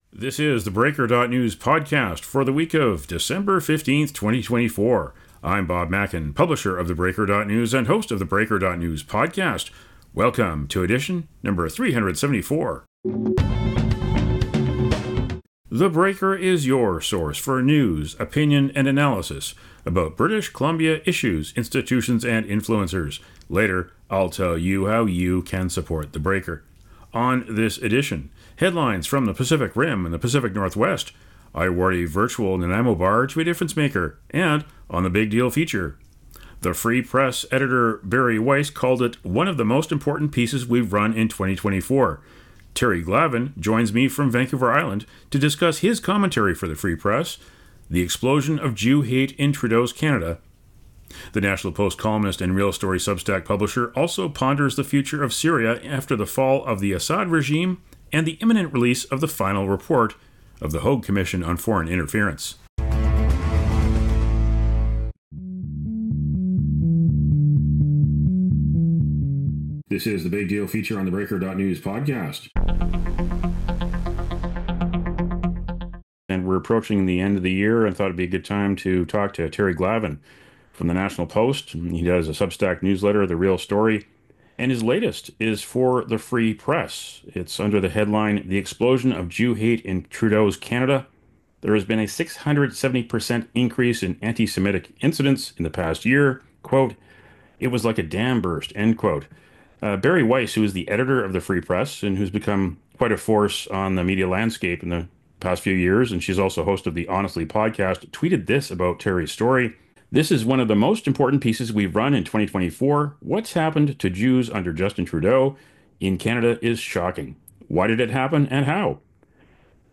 here’s a conversation about it all